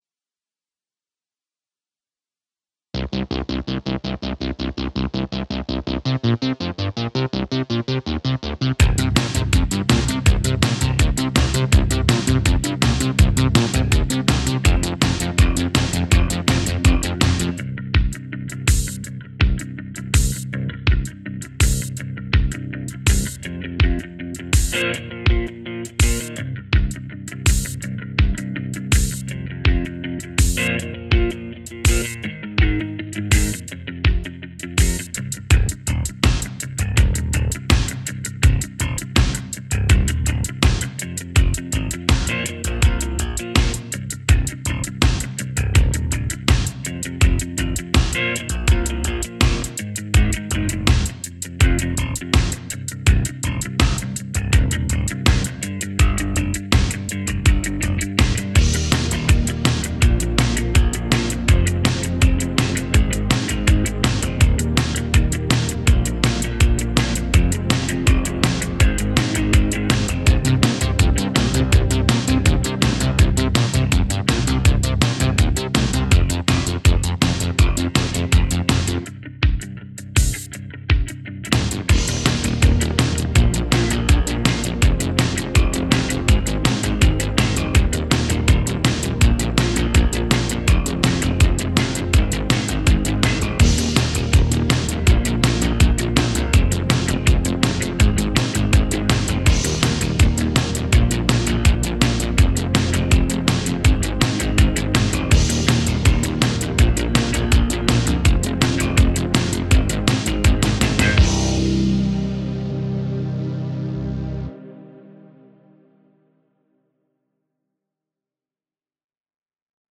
Here is the karaoke track to practice along with: